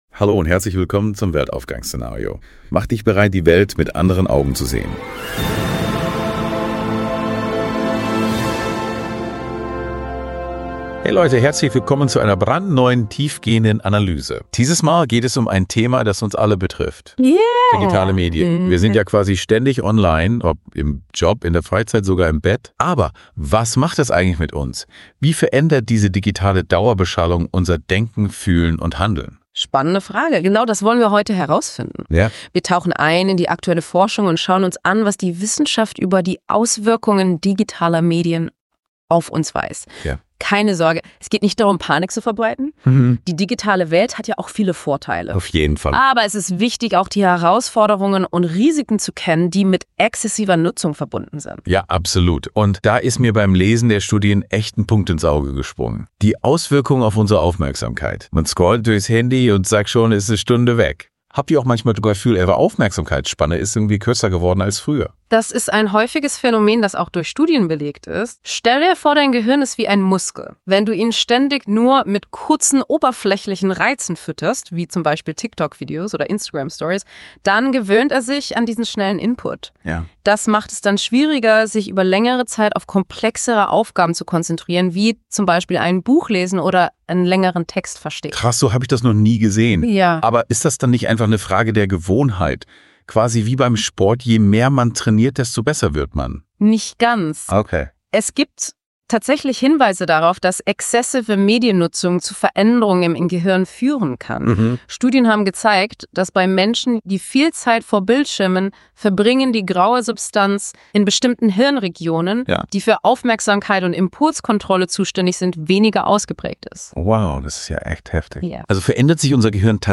Wir diskutieren die Vorteile, wie verbesserte Kommunikation, Effizienzsteigerung und grenzenlosen Zugang zu Wissen, aber auch die Schattenseiten: Datenschutzprobleme, Abhängigkeit und soziale Isolation. Experteninterviews, spannende Fallbeispiele und praxisnahe Tipps helfen dir, die digitale Welt bewusst und verantwortungsvoll zu nutzen.